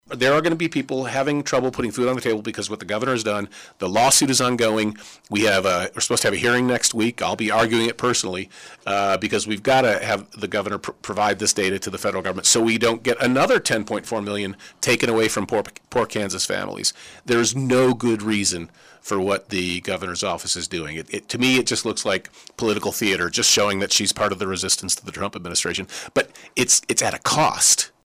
Kobach says his office is now preparing for the fight ahead.